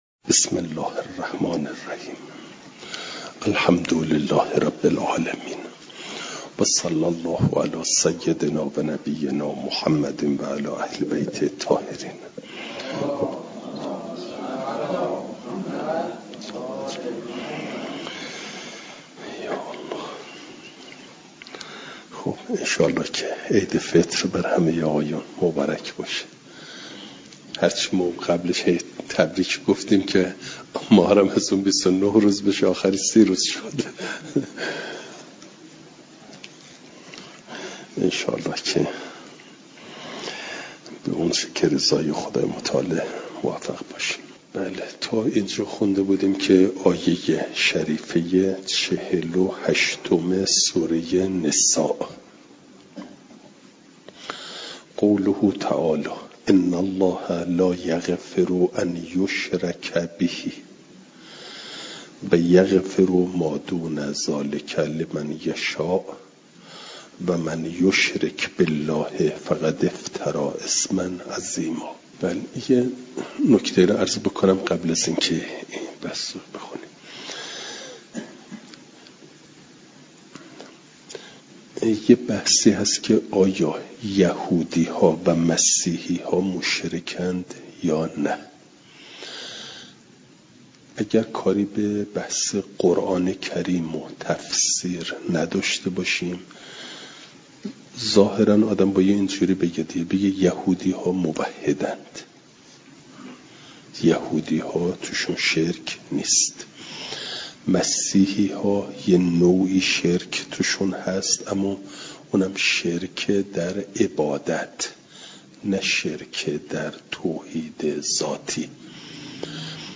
جلسه سیصد و هفتاد و یکم درس تفسیر مجمع البیان